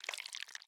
Minecraft Version Minecraft Version snapshot Latest Release | Latest Snapshot snapshot / assets / minecraft / sounds / block / honeyblock / slide3.ogg Compare With Compare With Latest Release | Latest Snapshot